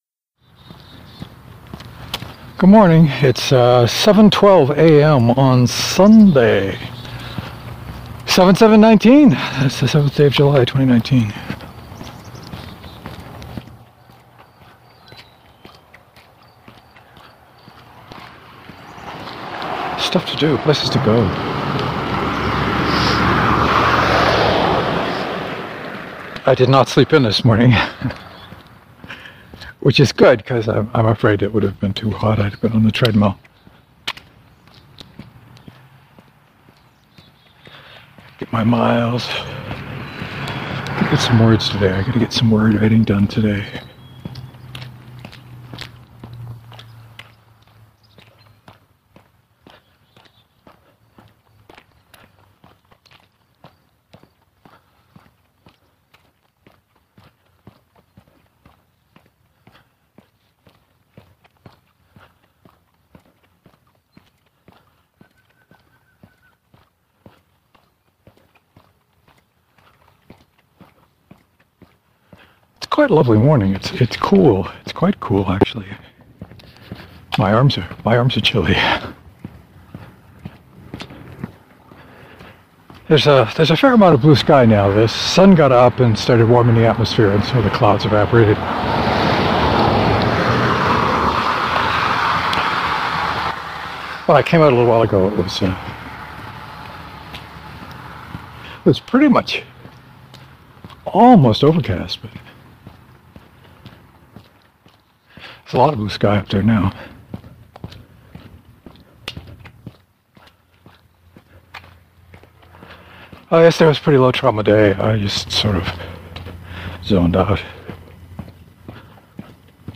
Not so much talking and mostly it was random rambling.